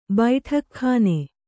♪ baiṭhak khāne